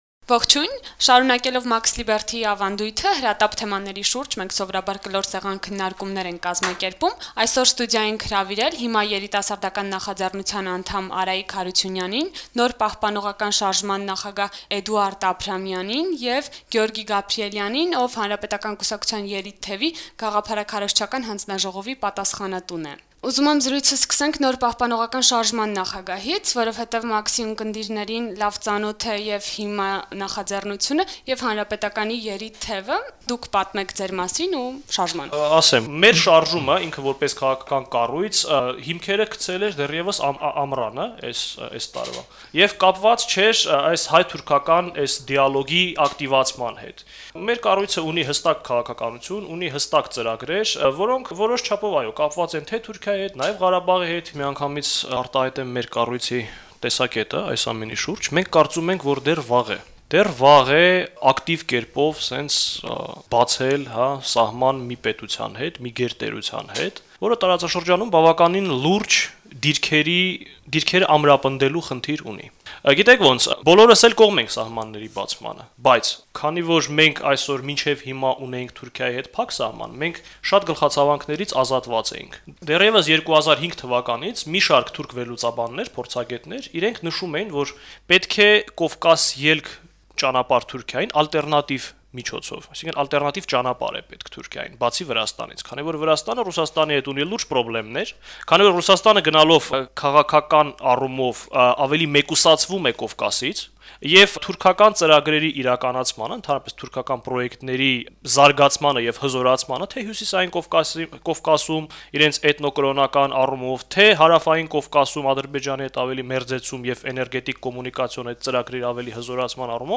«Կլոր սեղան»՝ հայ-թուրքական հարաբերությունների շուրջ
Հայ- թուրքական հարաբերությունների կարգավորման շուրջ ծավալվող զարգացումները “Մաքս լիբերթի”-ի կլոր սեղանի շուրջ քննարկում են տարբեր քաղաքական ուժերի երիտասարդ ներկայացուցիչներ: